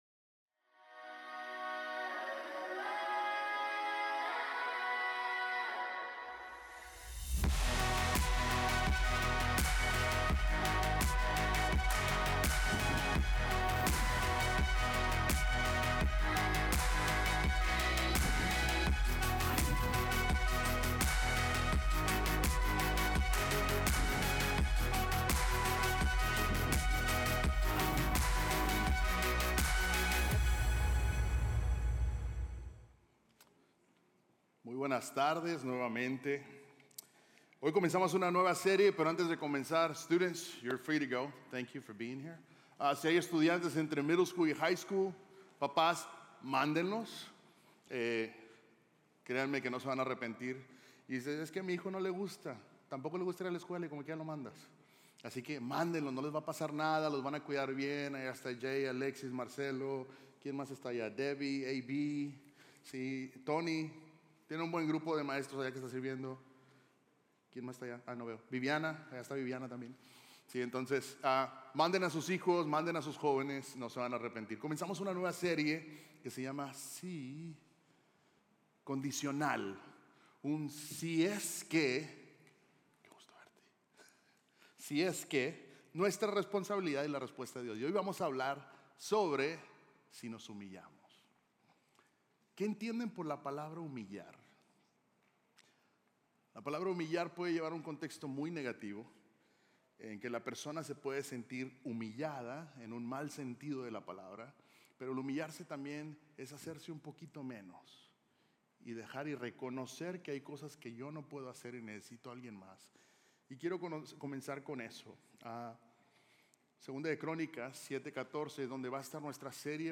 Sermones North Klein – Media Player